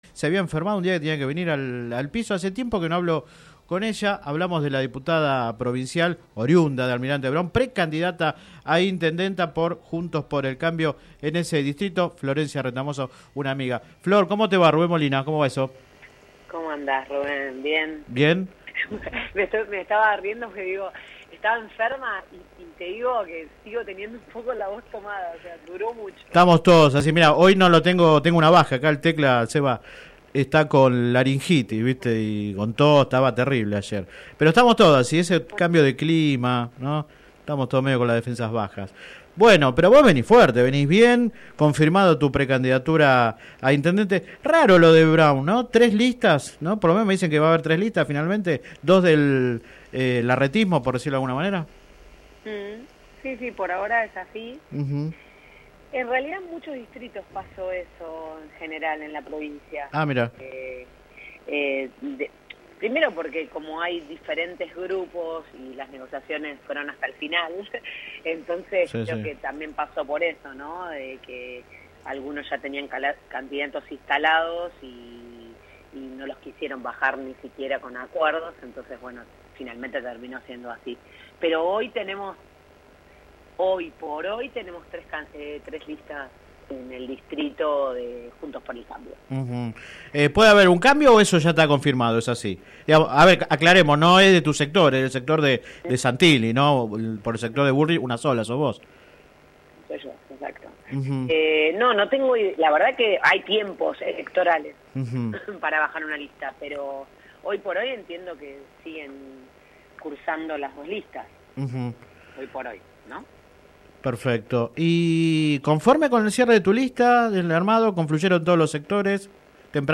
La precandidata por el bullrichismo en Almirante Brown, la diputada provincial Florencia Retamoso, aseguró que su lista es la que más “volumen” y “territorialidad” tiene de las tres listas presentadas por JXC en el distrito. En contacto con el programa radial Sin Retorno se mostró confiado de cara a las PASO y expresó un fuerte respaldo a Patricia Bullrich y Néstor Grindetti.
Click acá entrevista radial